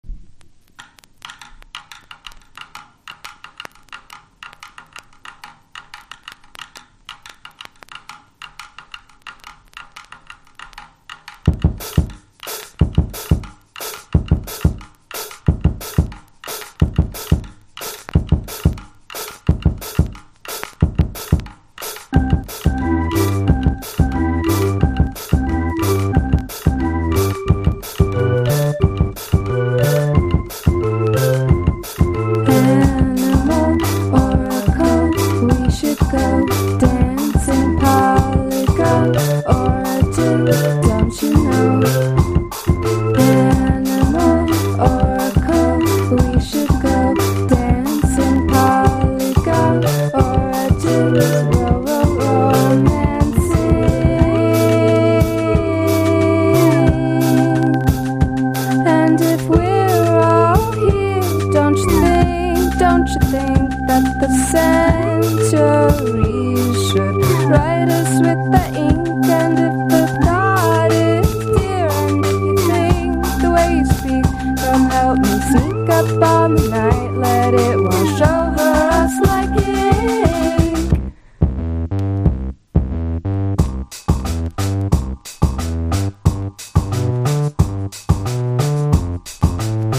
NEO ACOUSTIC / GUITAR POP
ユルユル気持ちいい脱力トロピカル・ポップ・チューン！！